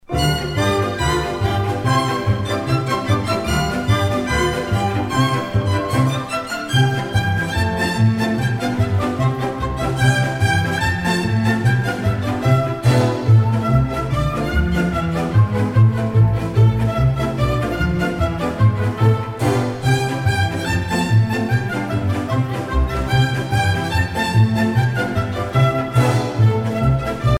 danse